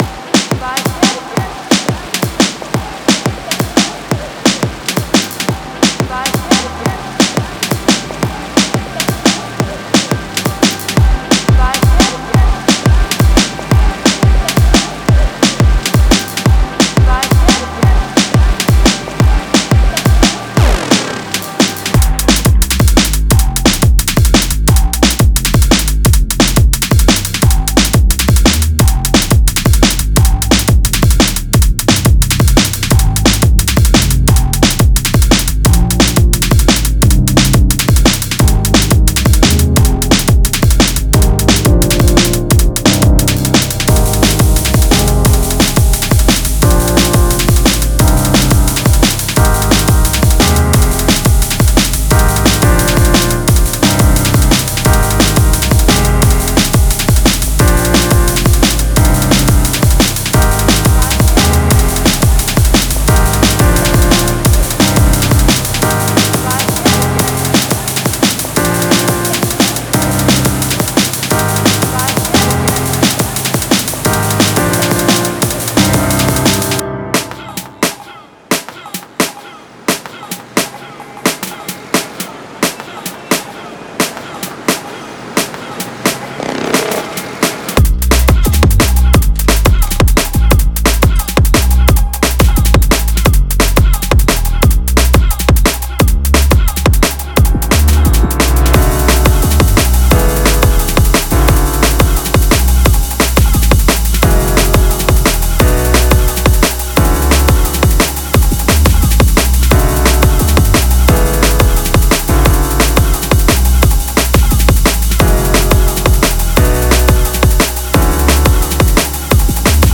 Drum & Bass
175 BPM